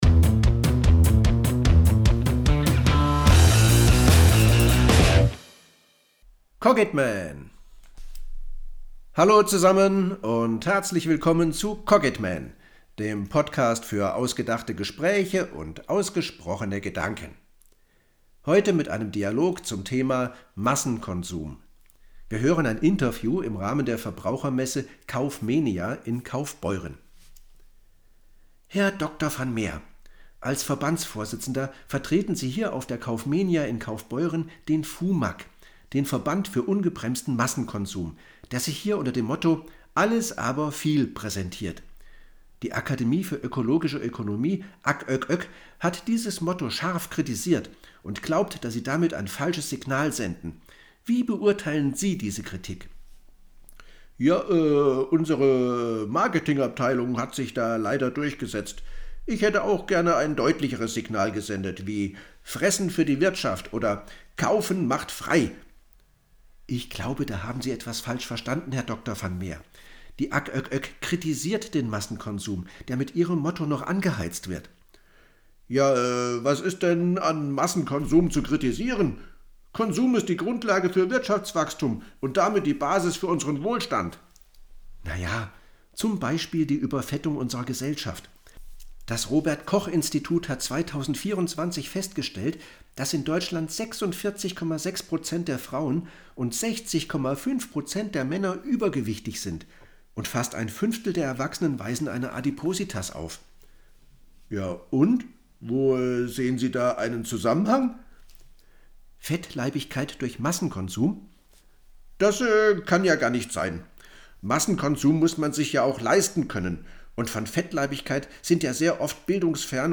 Dialog_Massenkonsum.mp3